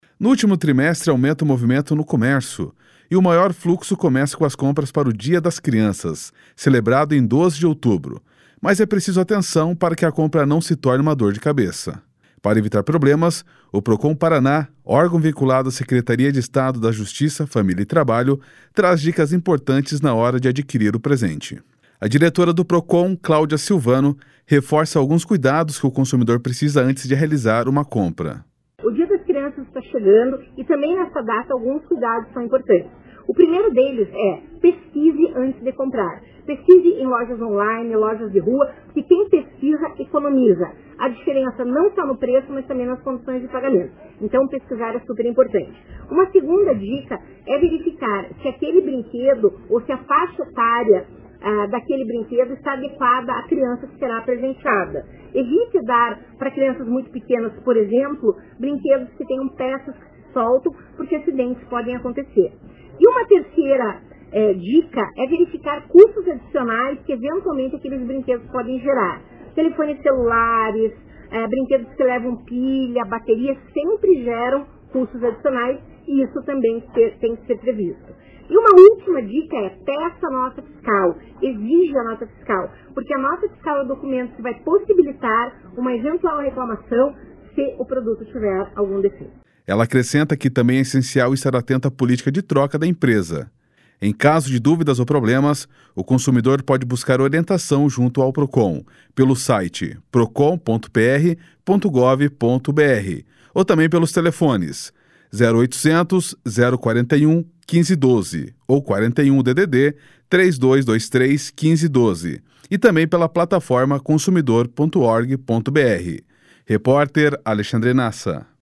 A diretora do Procon/PR, Cláudia Silvano, reforça alguns cuidados que o consumidor precisa antes de realizar uma compra.//SONORA CLÁUDIA SILVANO//